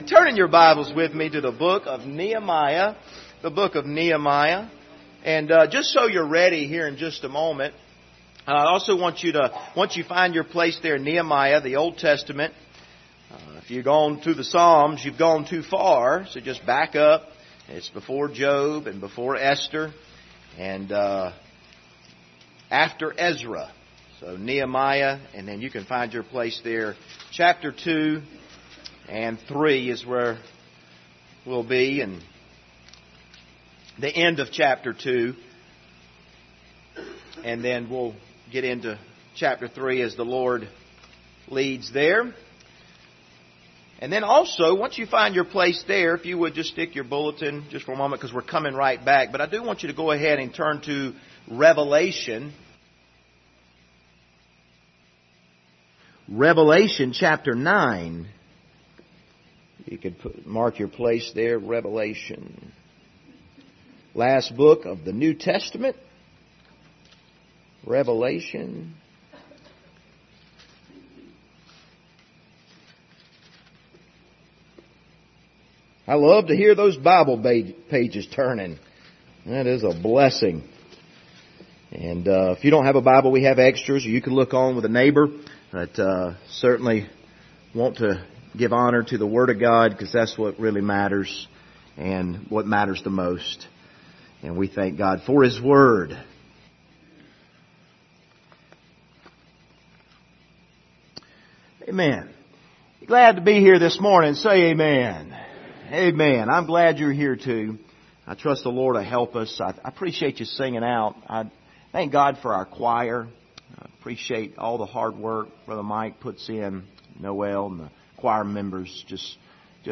Passage: Nehemiah 2:17-20 Service Type: Sunday Morning